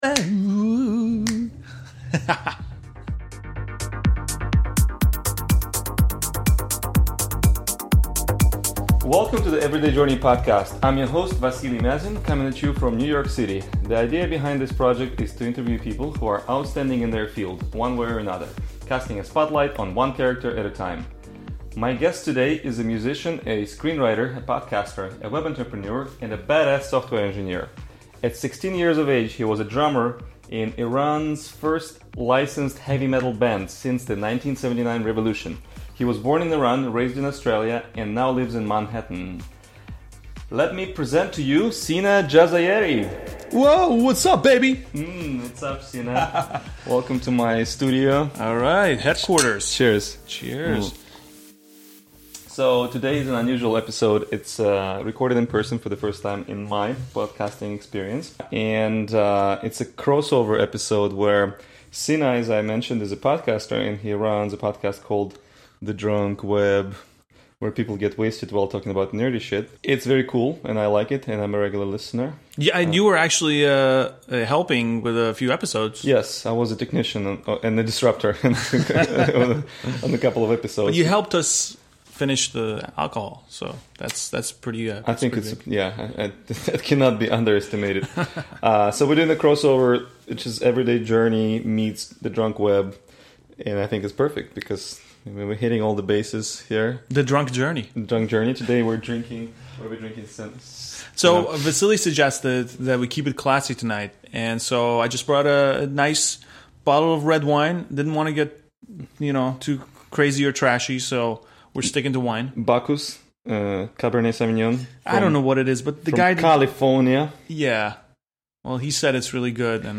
Informal interviews with outstanding people